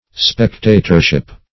Search Result for " spectatorship" : The Collaborative International Dictionary of English v.0.48: Spectatorship \Spec*ta"tor*ship\, n. 1.